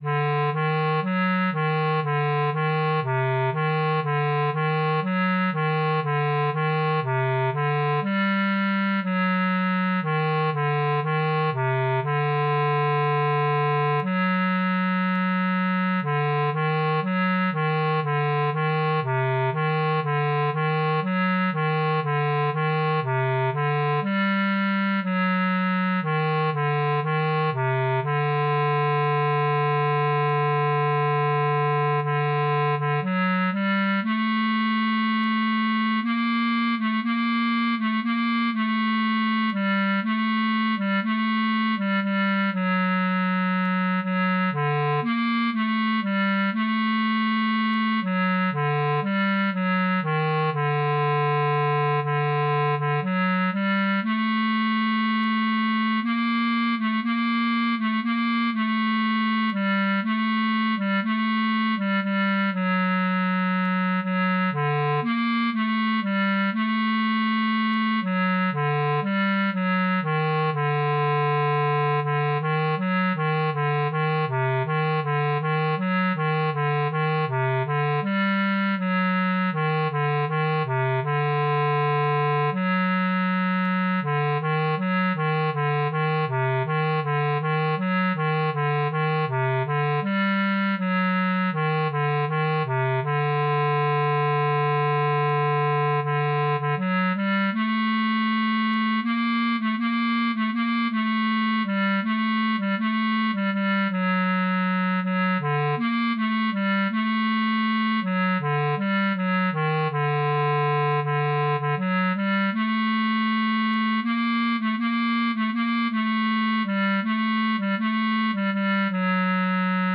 G minor ♩= 60 bpm